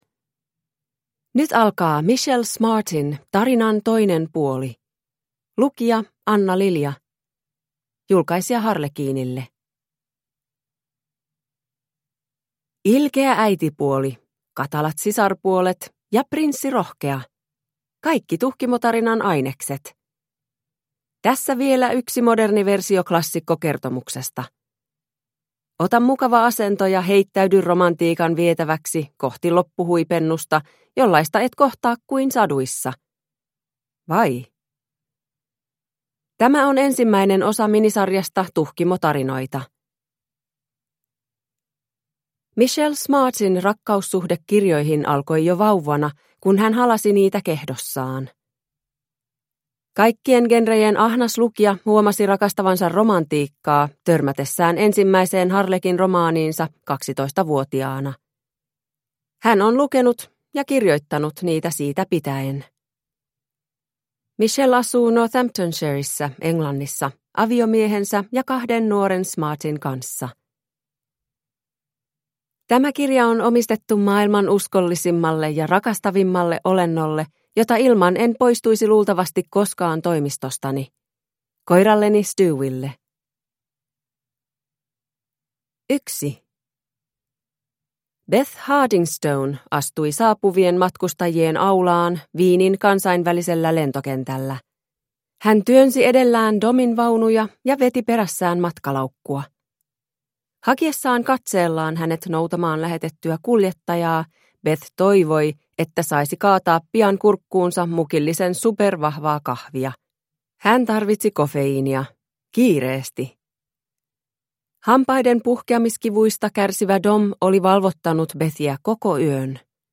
Tarinan toinen puoli (ljudbok) av Michelle Smart